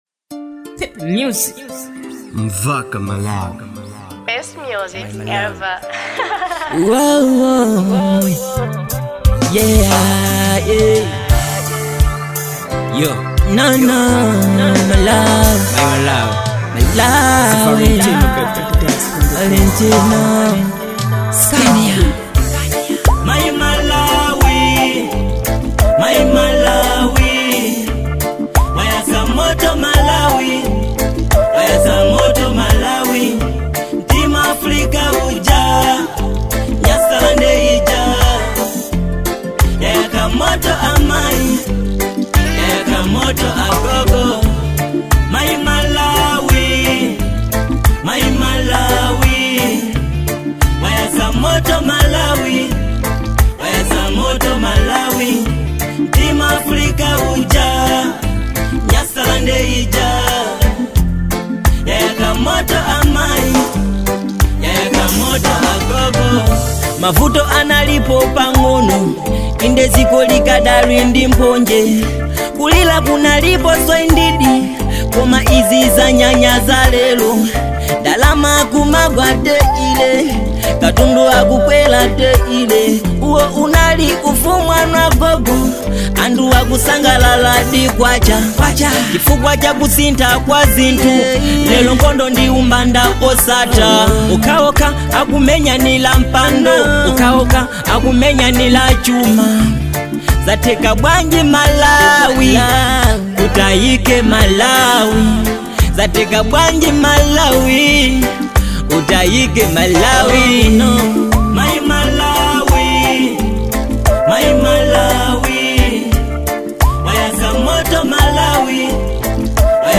type: love song